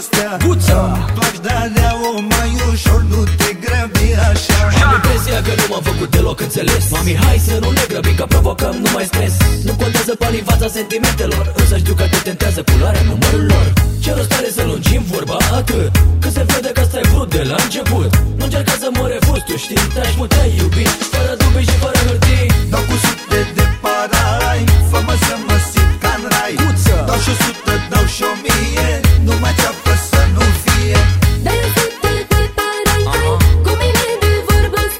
Жанр: Поп музыка / Танцевальные